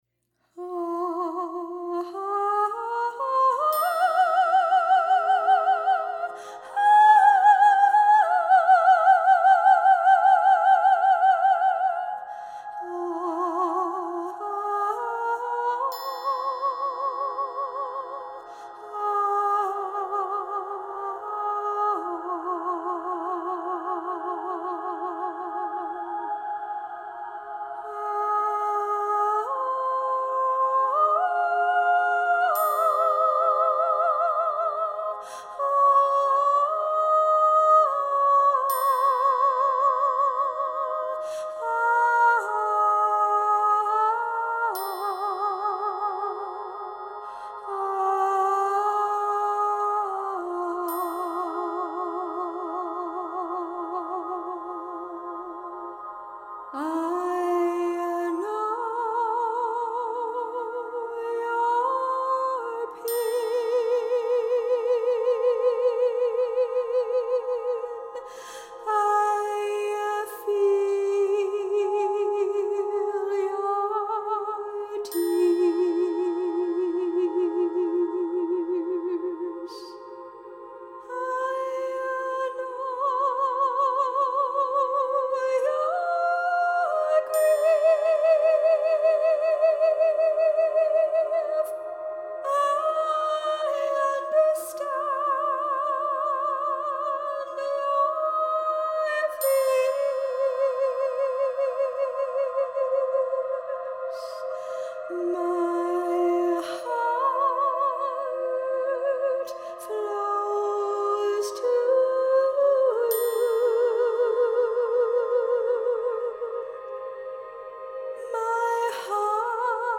Drum
Shaker and Rattle
Triangle
Soundscape